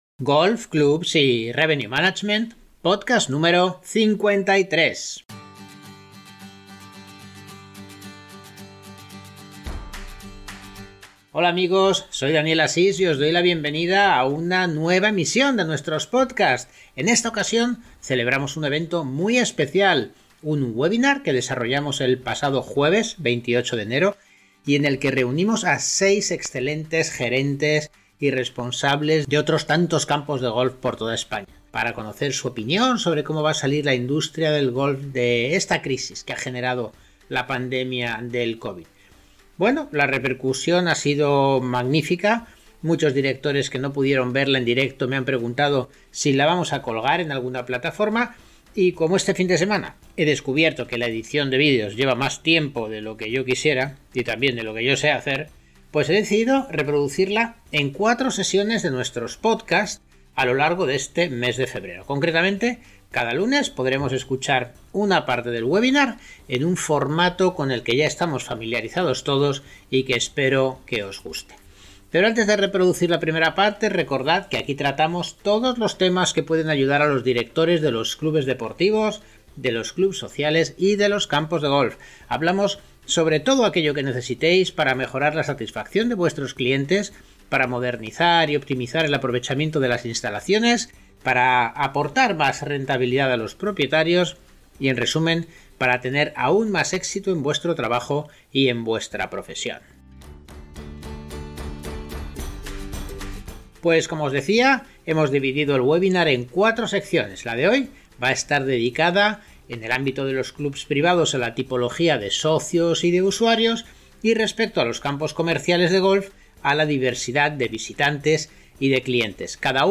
¿Qué sucede cuando seis renombrados gerentes y representantes de clubes de socios y de campos de golf se sientan a debatir, opinar, y exponer su visión sobre la realidad de su sector?